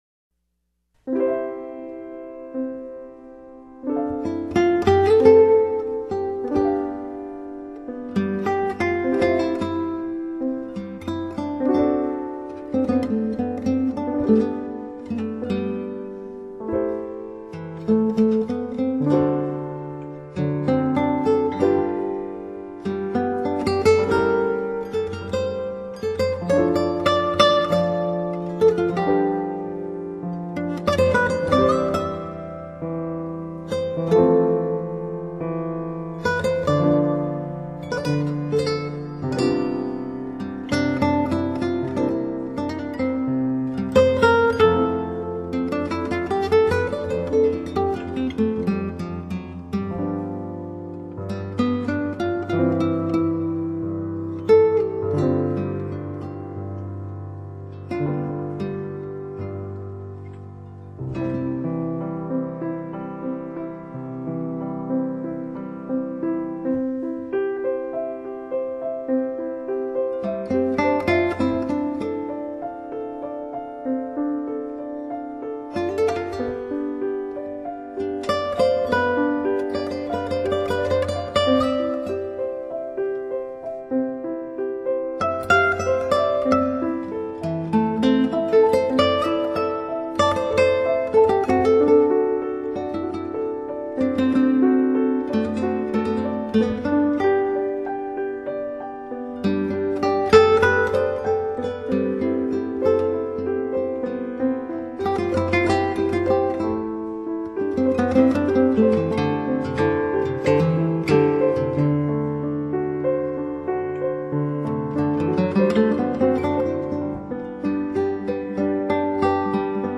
Bach, Improvisation, Prelude in C